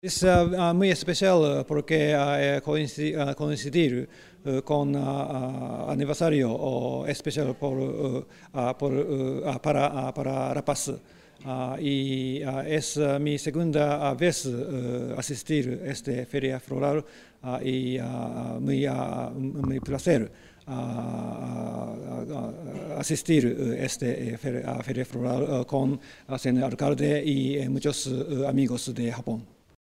embajador_de_japon_hideki_asari.mp3